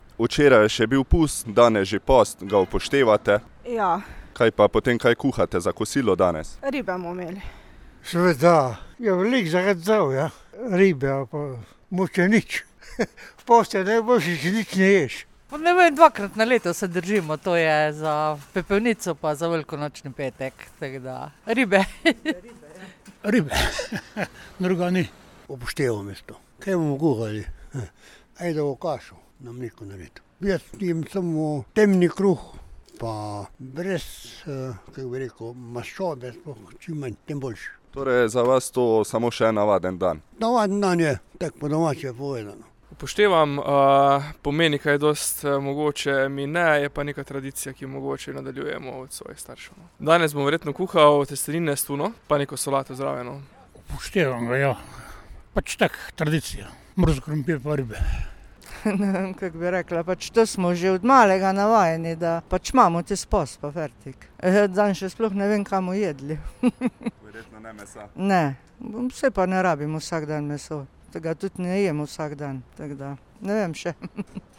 Oznake: pustanketapostpepelnična sreda